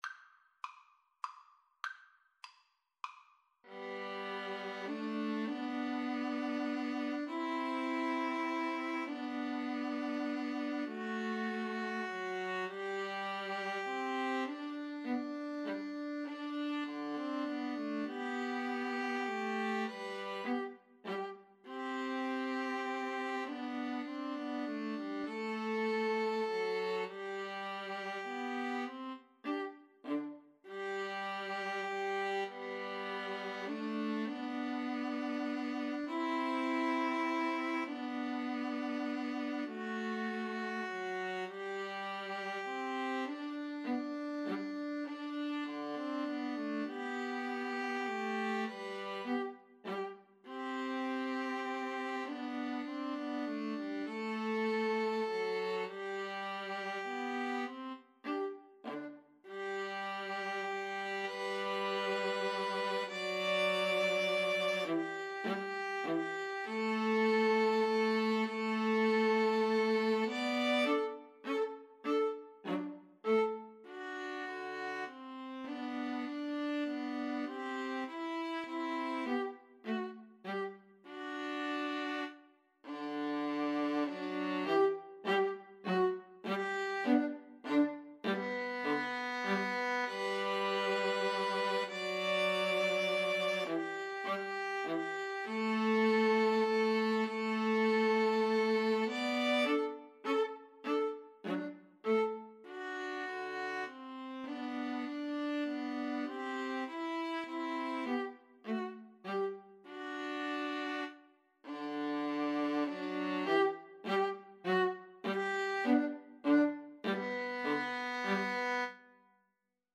Classical (View more Classical 2-violins-viola Music)